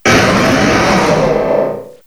cry_not_mega_aggron.aiff